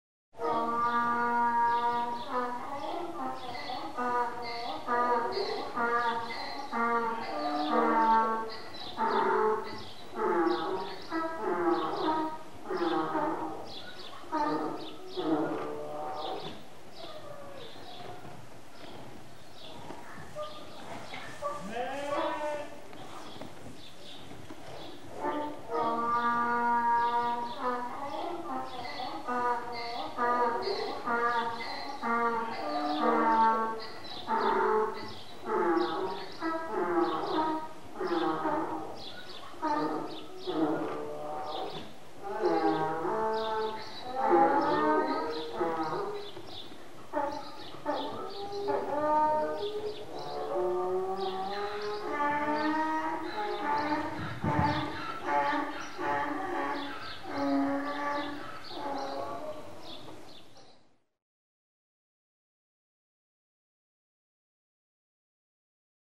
جلوه های صوتی
دانلود صدای خرها از ساعد نیوز با لینک مستقیم و کیفیت بالا
برچسب: دانلود آهنگ های افکت صوتی انسان و موجودات زنده دانلود آلبوم صدای خر و الاغ از افکت صوتی انسان و موجودات زنده